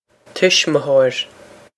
tuismitheoir tish-mih-ho-ir
This is an approximate phonetic pronunciation of the phrase.